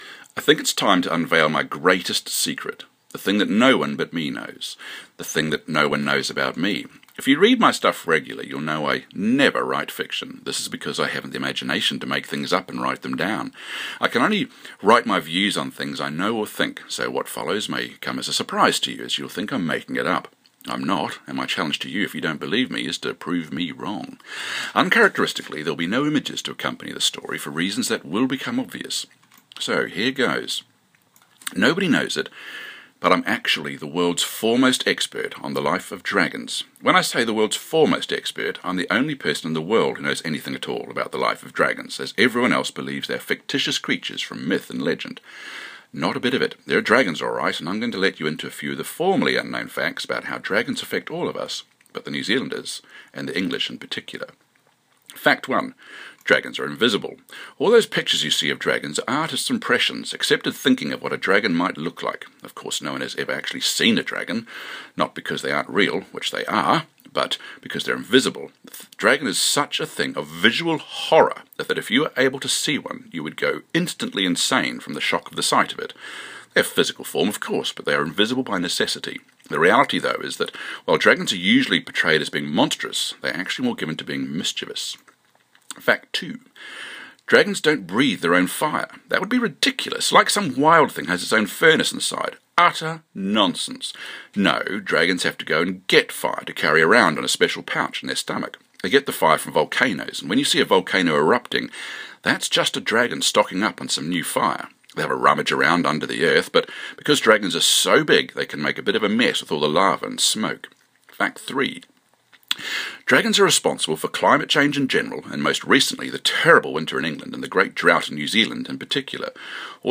If you like your stories read to you, I’ve done that and there is an audio file of this as well.